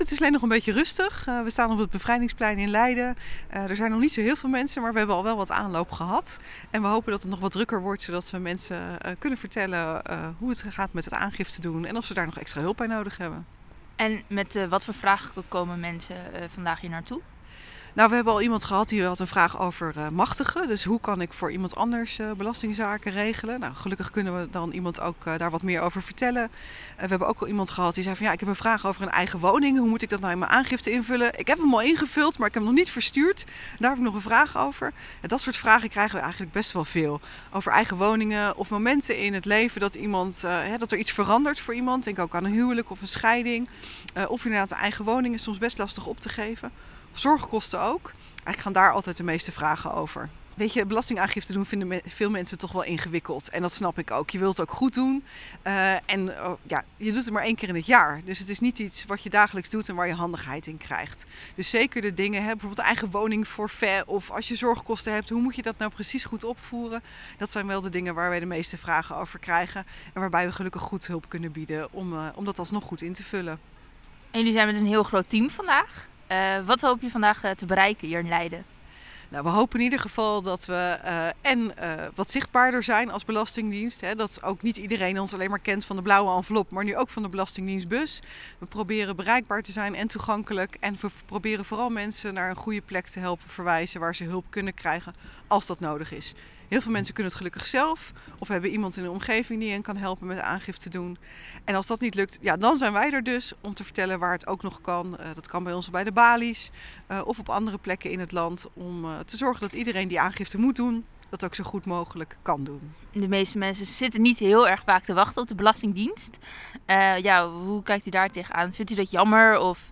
Ondanks de koude wind stond de blauwe brigade van de Belastingdienstbus vanochtend op het Bevrijdingsplein om mensen te helpen met hun belastingaangifte.